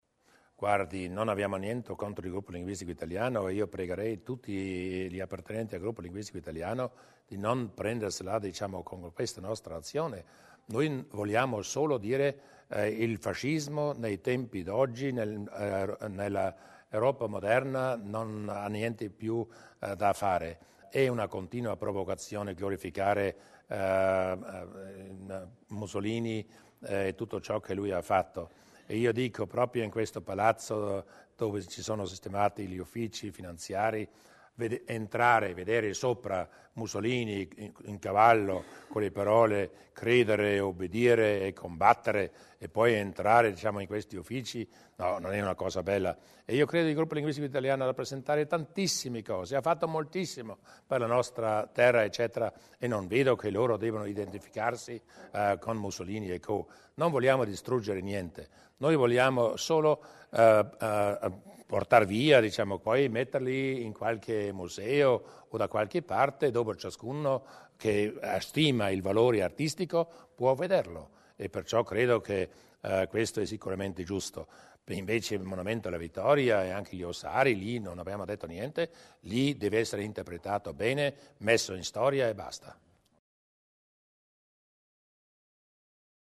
Lo ha annunciato Luis Durnwalder durante la conferenza stampa del lunedì.